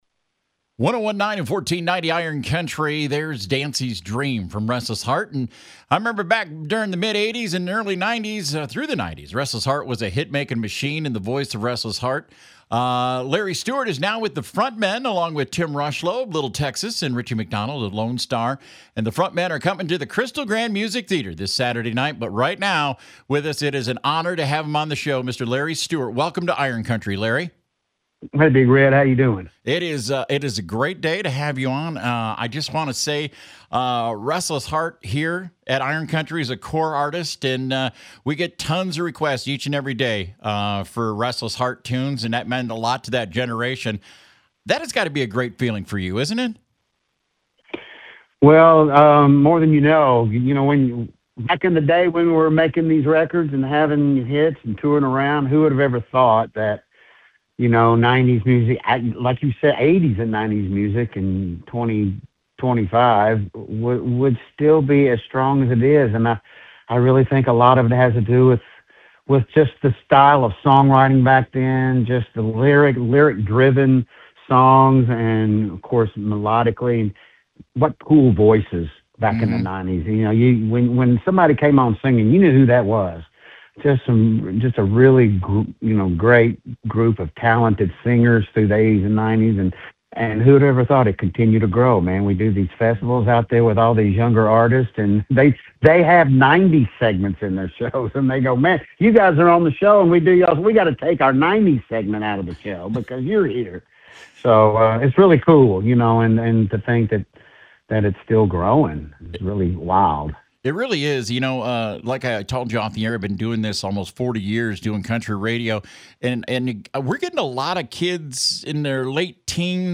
Larry Stewart Interview